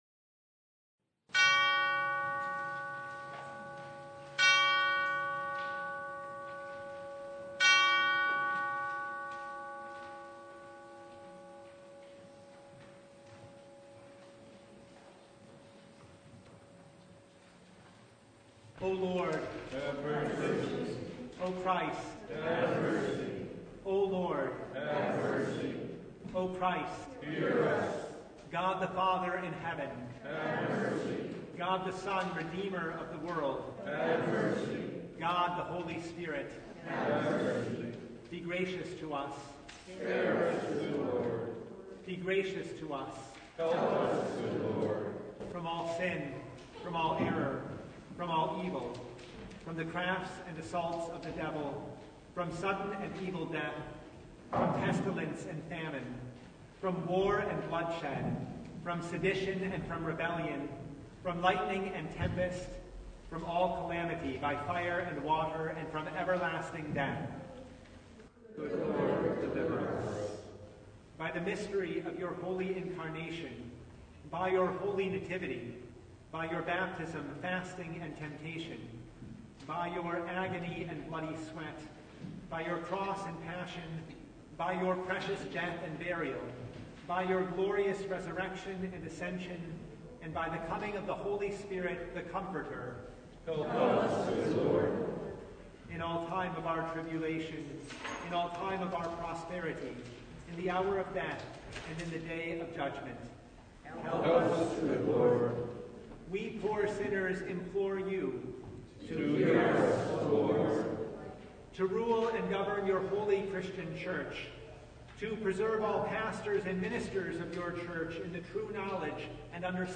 Passage: Exodus 7: 1-25 Service Type: Lent Midweek Noon
Full Service